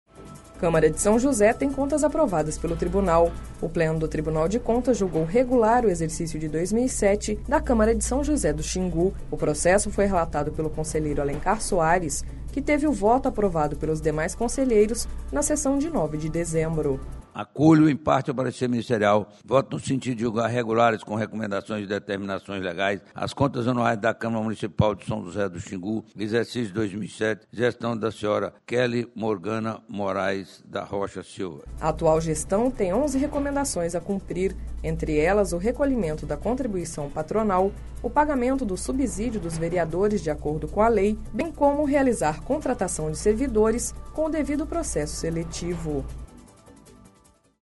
Sonora: Alencar Soares – conselheiro do TCE-MT